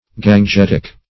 Search Result for " gangetic" : The Collaborative International Dictionary of English v.0.48: Gangetic \Gan*get"ic\, a. Pertaining to, or inhabiting, the Ganges River; as, the Gangetic shark.
gangetic.mp3